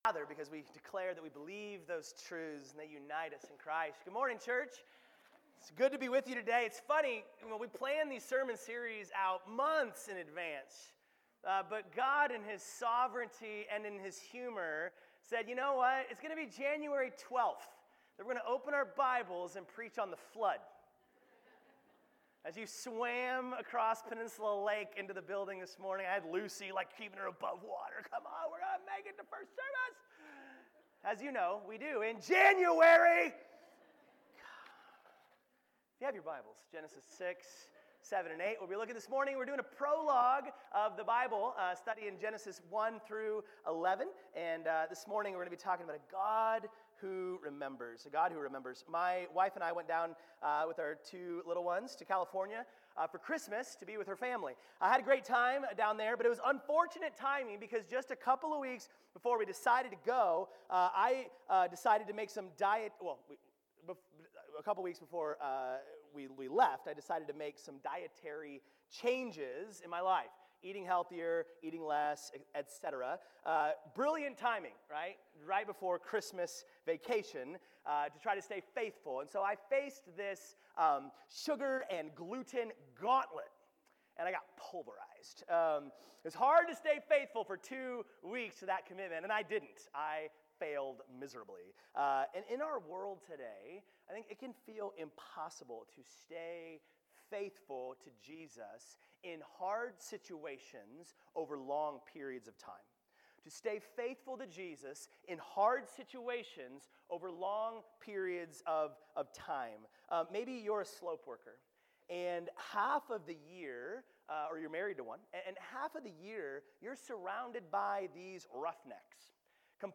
Sermons | Peninsula Grace Church